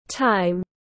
Thời gian tiếng anh gọi là time, phiên âm tiếng anh đọc là /taɪm/
Time /taɪm/